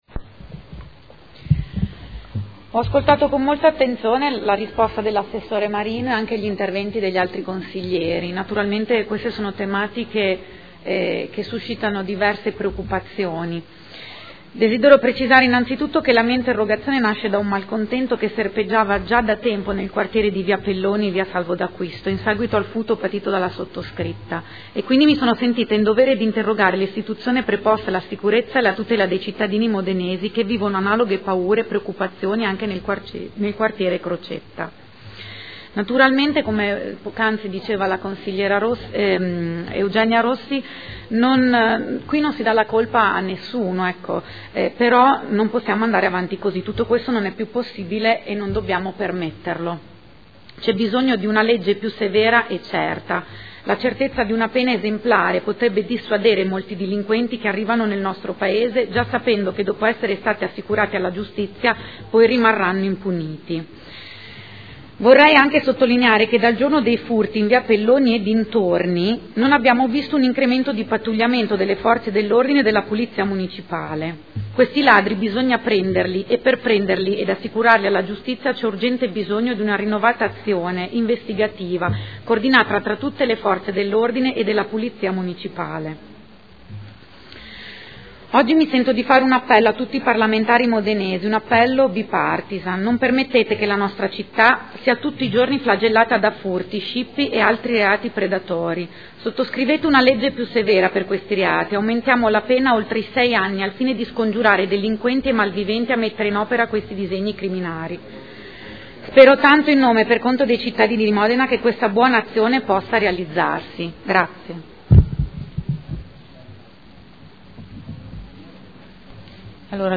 Seduta del 19/09/2013. Dibattito su interrogazione della consigliera Maienza (P.D.) avente per oggetto: “Sicurezza.